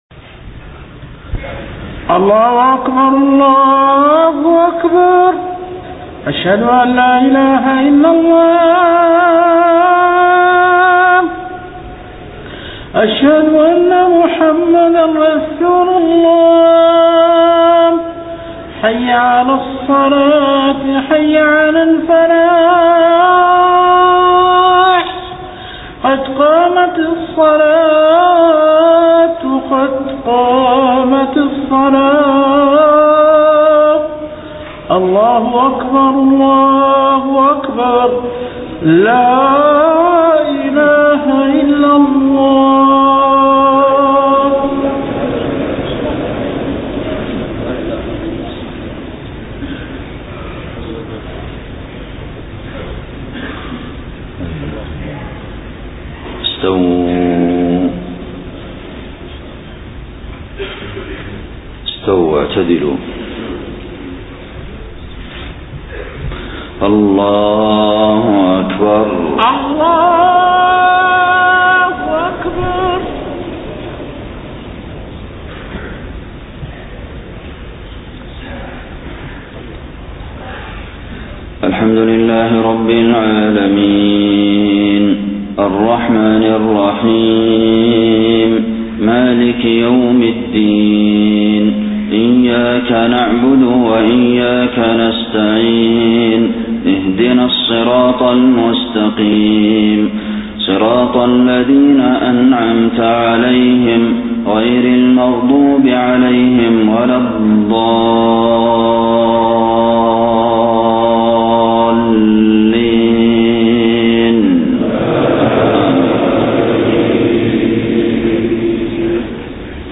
صلاة الفجر 16 ربيع الأول 1431هـ من سورة الأنعام 95-107 > 1431 🕌 > الفروض - تلاوات الحرمين